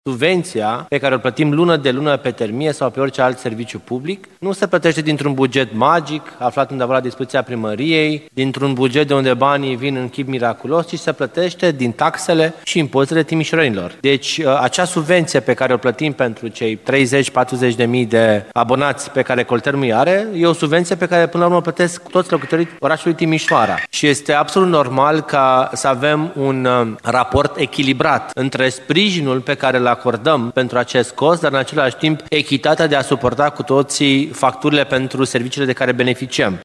Viceprimarul Ruben Lațcău a spus că majorarea se impune și ca o măsură echitabilă față de cei care au centrale proprii.